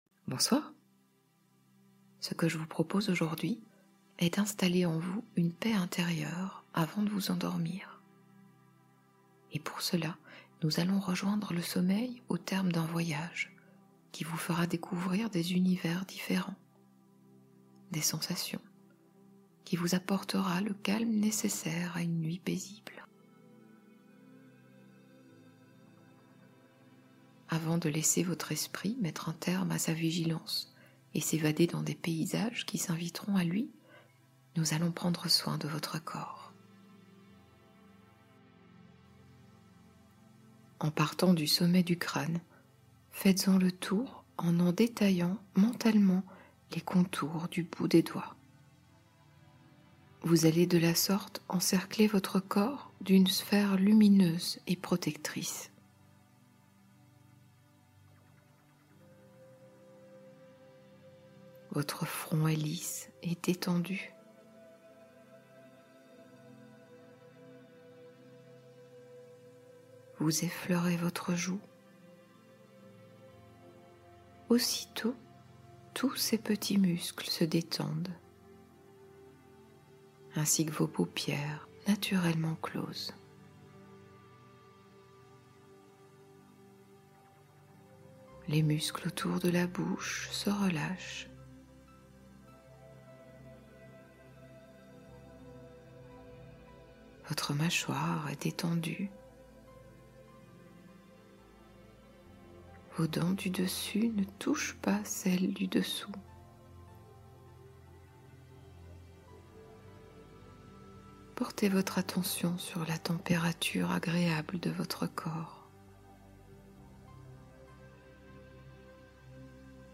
Sommeil au bout du voyage : hypnose apaisante